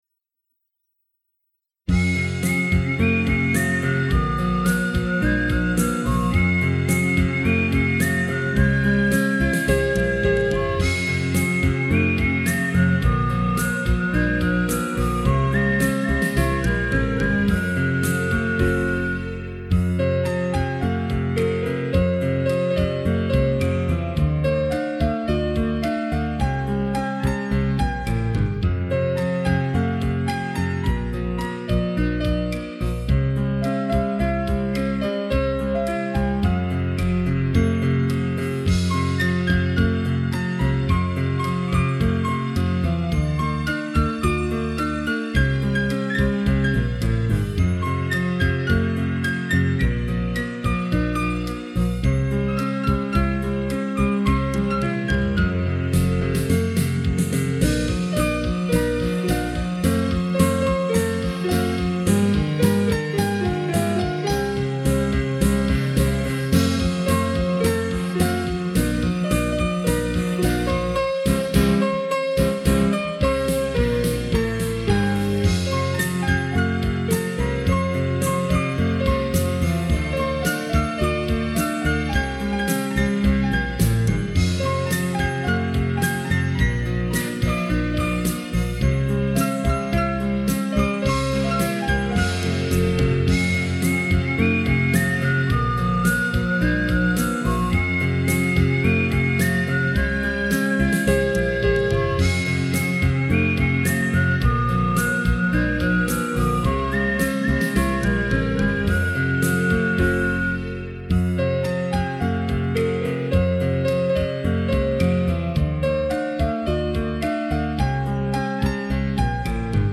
让伤感在琴声中融化。